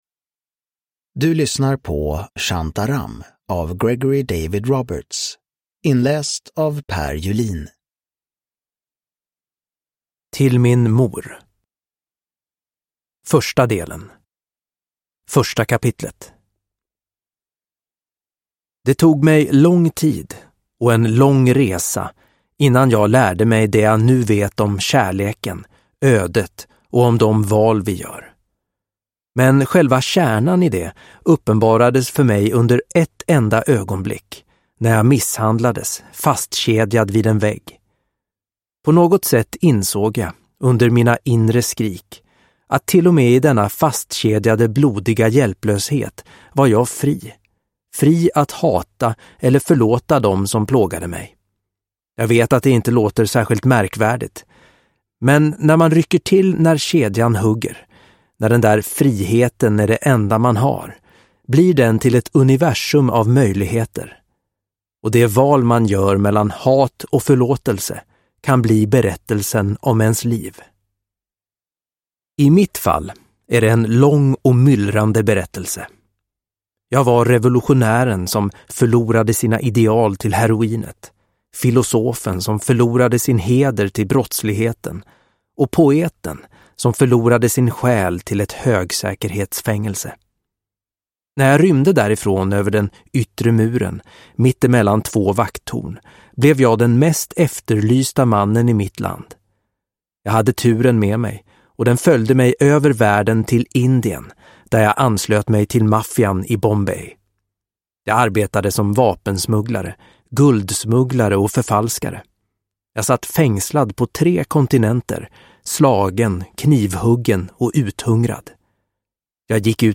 Shantaram – Ljudbok – Laddas ner